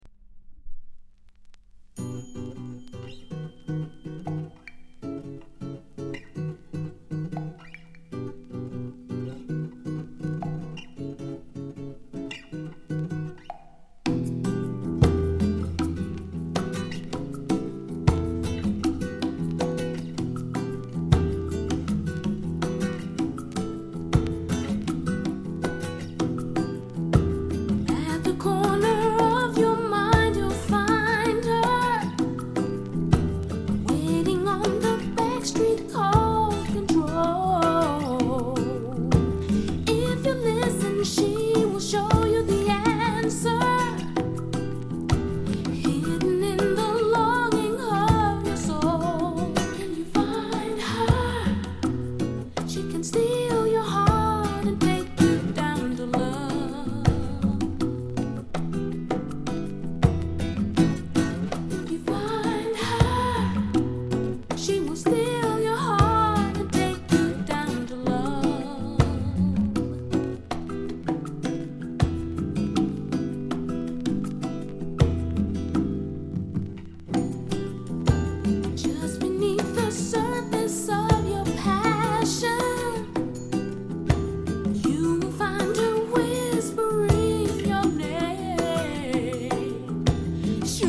category R&B & Soul